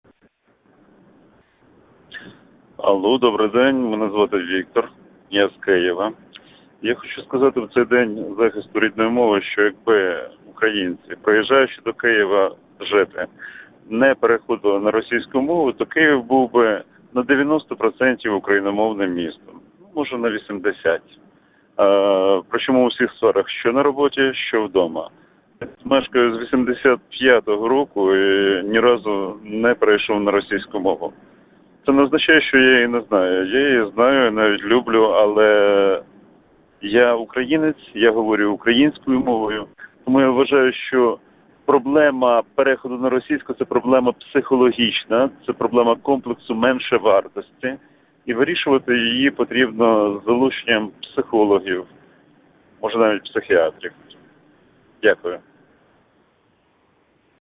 Embed share Повідомлення в ефір з автовідповідача.